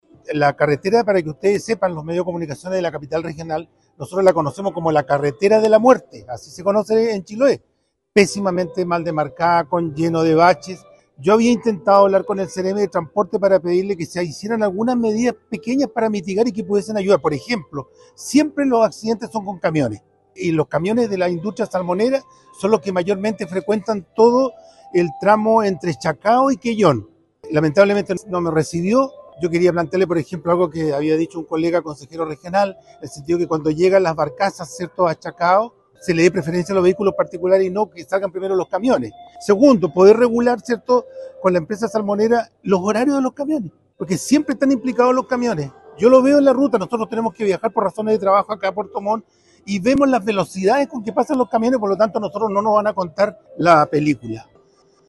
Una conferencia de prensa, ofrecieron en Puerto Montt, los consejeros regionales de la Provincia de Chiloé, para presentar sus inquietudes y su posición, con respecto al proyecto de la doble vía, que, en su primera fase, considera el tramo entre Chacao y Chonchi.